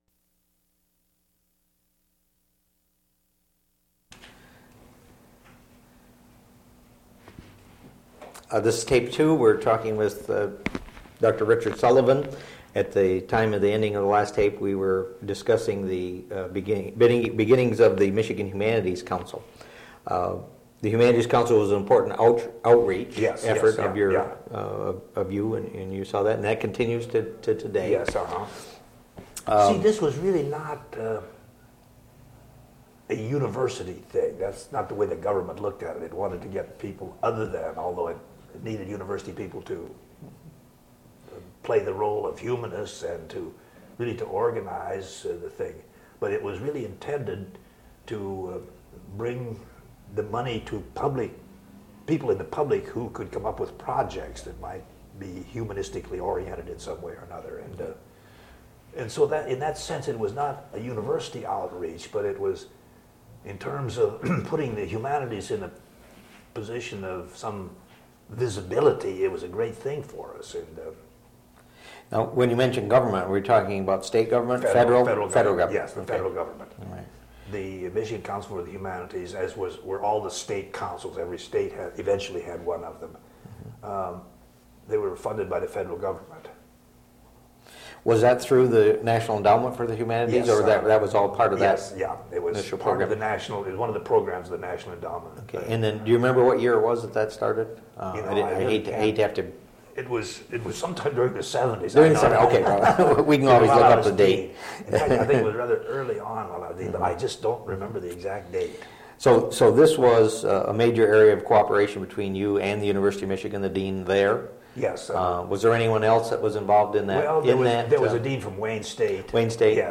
Interview
Audio/mp3 Original Format: Audiocassettes Resource Identifier